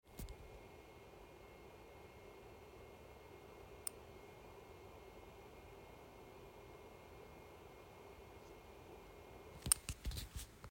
Seems that the fans air flow is clearly audible.
This is what is sounds like after a few hours of use.